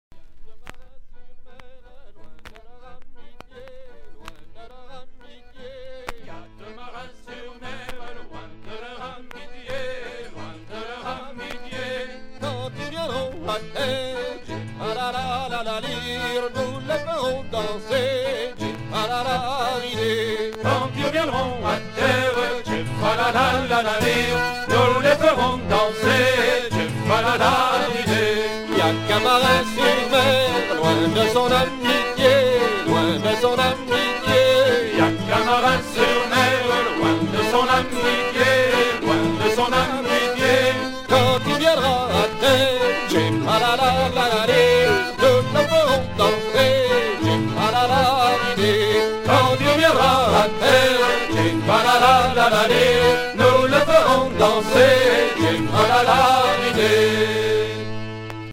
danse : hanter-dro
Pièce musicale éditée